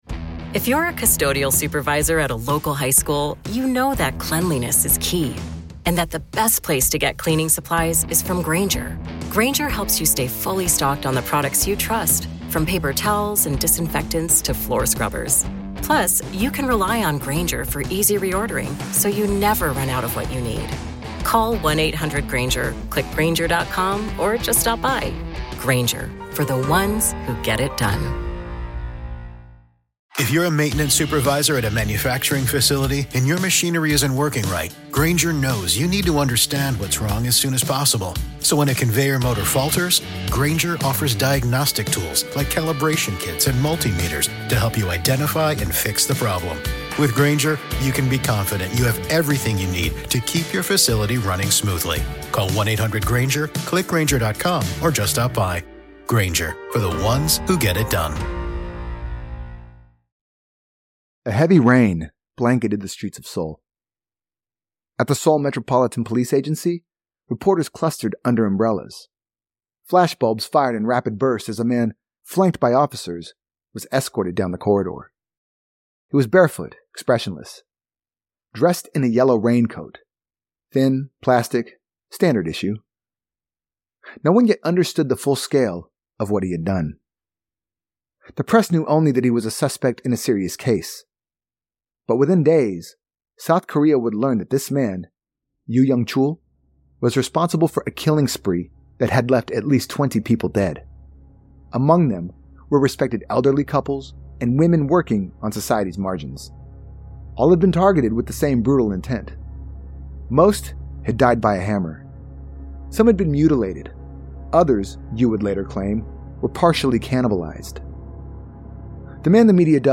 Background track